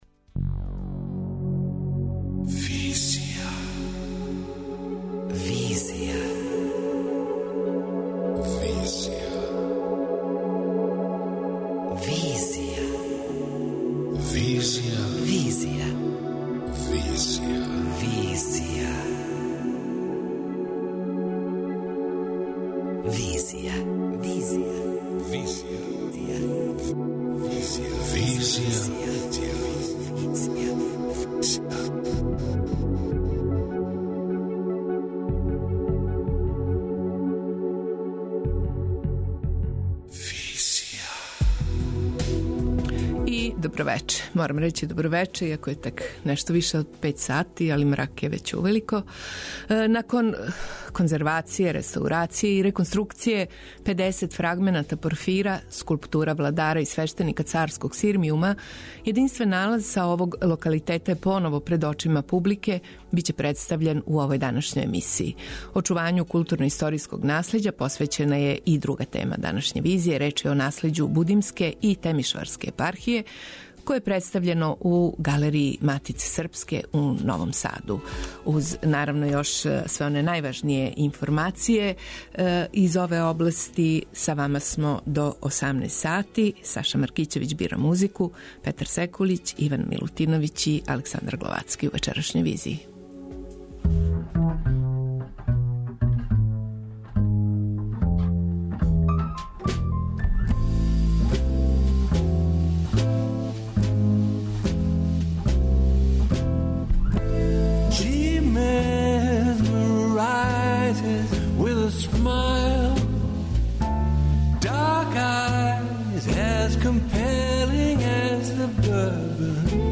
преузми : 24.77 MB Визија Autor: Београд 202 Социо-културолошки магазин, који прати савремене друштвене феномене.